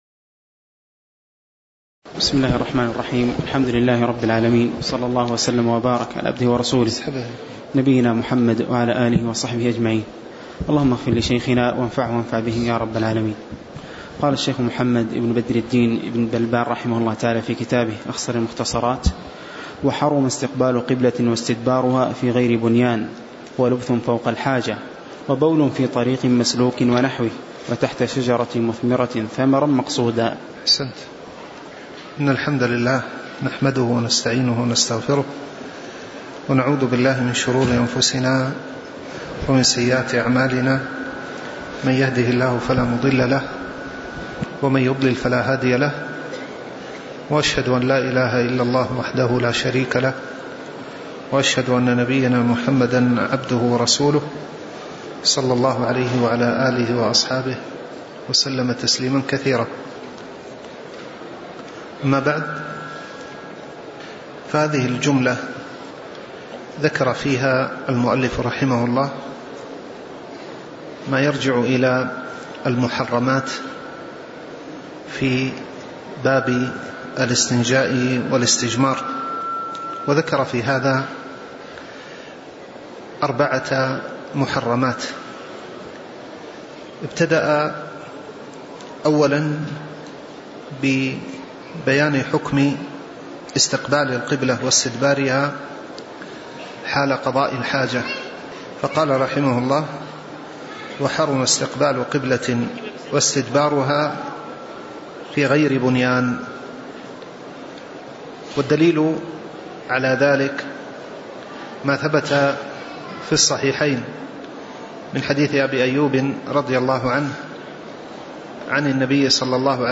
تاريخ النشر ١١ صفر ١٤٣٩ هـ المكان: المسجد النبوي الشيخ